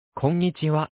音声合成拡張機能は、2018年10月に追加されたScratch 3.0拡張機能で、スプライトに合成音声をしゃべらせることができるものである。
アルトとねずみは女声であり、テノールと巨人は男声である。
アルトは